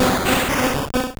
Cri de Krabboss dans Pokémon Or et Argent.